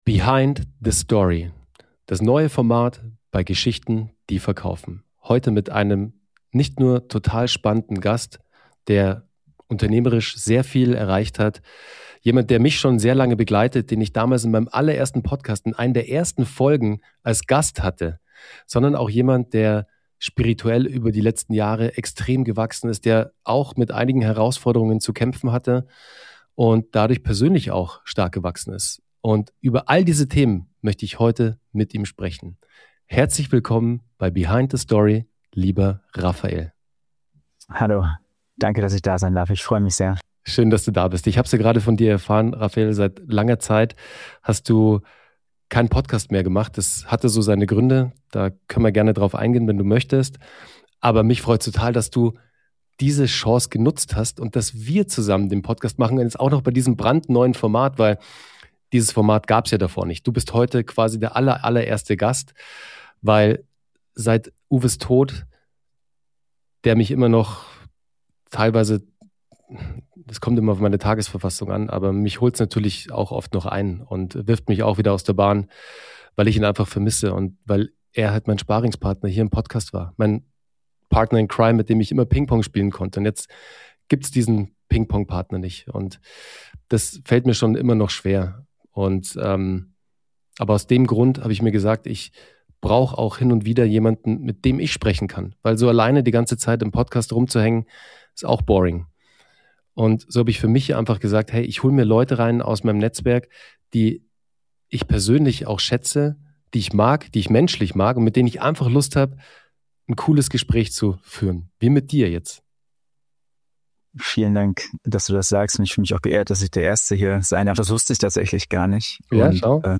Es geht um: • Tod und Legacy • Meditation als Überlebensstrategie • Breathwork als Durchbruch • Spiritualität ohne Esoterik • Unternehmertum zwischen Raketenstart und Nervenzusammenbruch Eine ehrliche, ungefilterte Unterhaltung über Erfolg, Schmerz, Heilung – und die Frage: Was bleibt wirklich von uns?